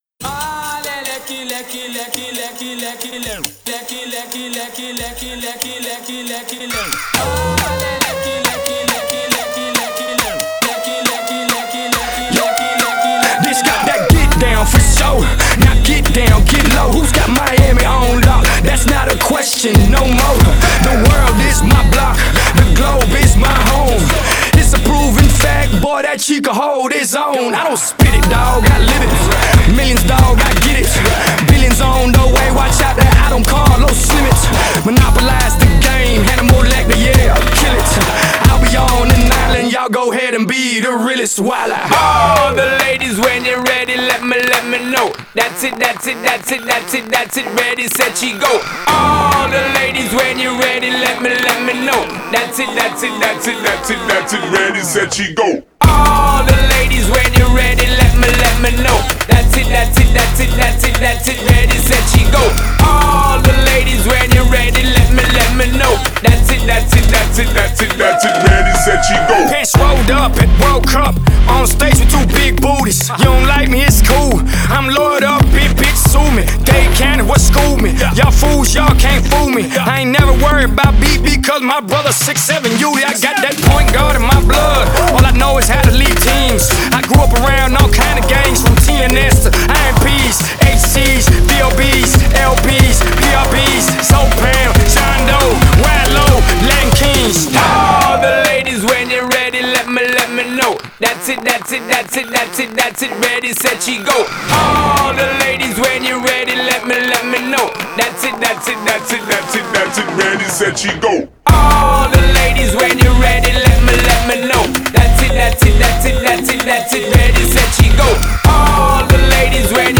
Pop, Hip Hop, Dance, Latin Pop